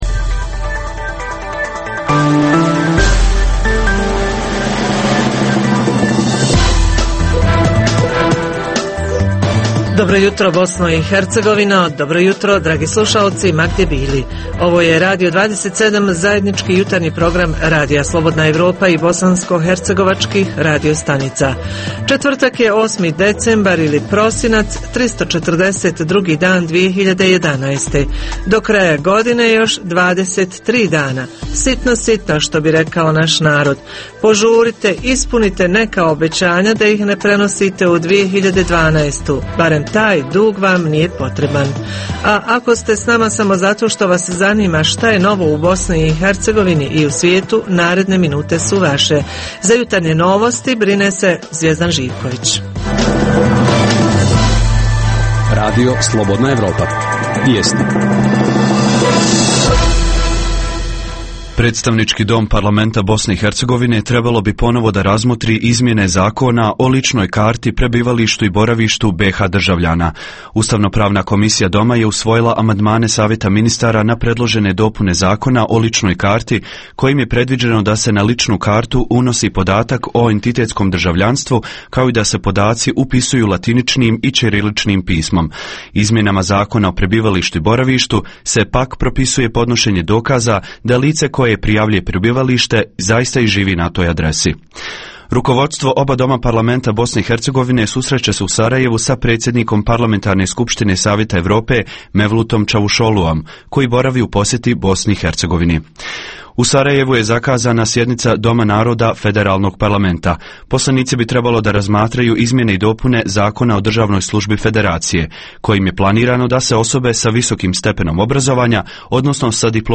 Tema jutra: humane i humanitarne akcije za različite namjene a prije svega za spas i ozdravljenje sugrađana: koncerti, telefonski pozivi, dobrovoljni novčani prilozi, itd Reporteri iz cijele BiH javljaju o najaktuelnijim događajima u njihovim sredinama.
Redovni sadržaji jutarnjeg programa za BiH su i vijesti i muzika.